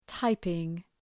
Προφορά
{‘taıpıŋ}